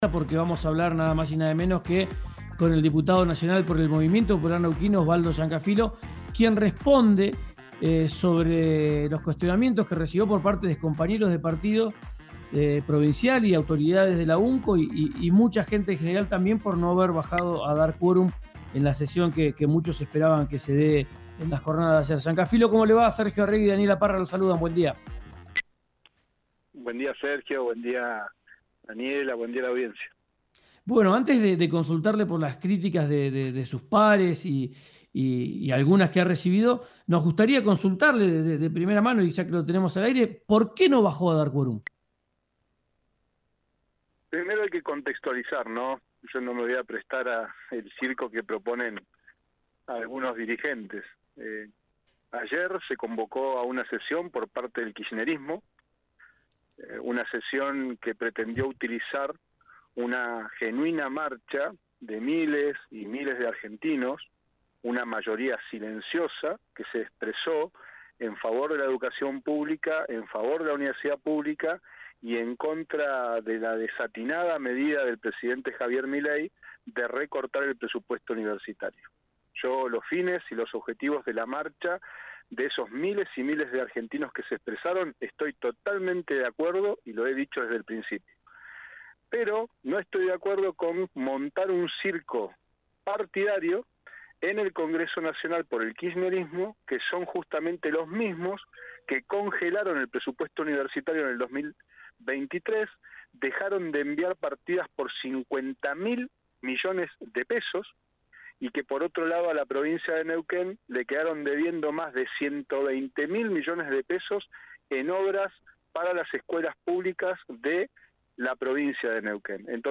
El diputado nacional explicó en RÍO NEGRO RADIO por qué no dio quórum en la sesión de la Cámara de Diputados que ayer buscaba discutir el presupuesto de las universidades. También anticipó su posición frente a la Ley Bases.
Escuchá al diputado nacional del Movimiento Popular Neuquino, Osvaldo Llancafilo en RÍO NEGRO RADIO